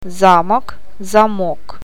O ääntyy painottomana a :n kaltaisena, e ja я i :n tai ji :n tapaisena äänteenä.
Paino eri tavulla saa aikaan merkityseron: